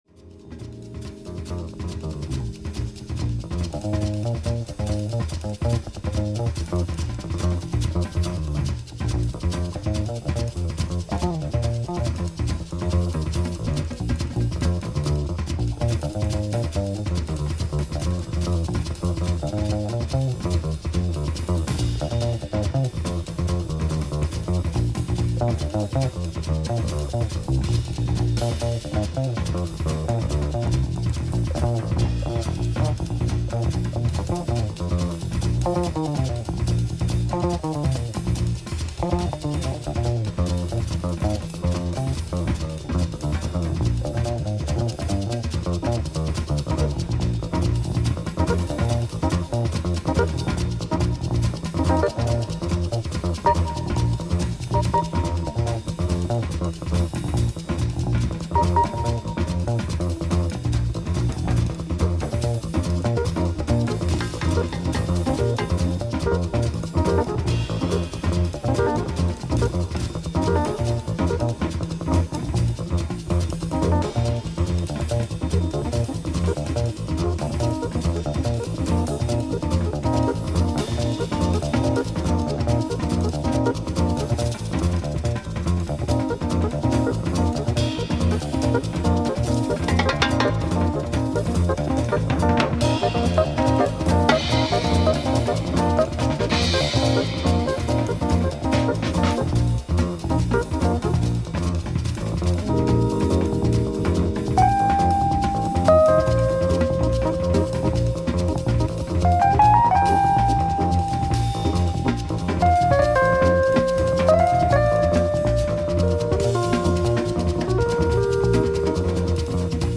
German modal and free jazz.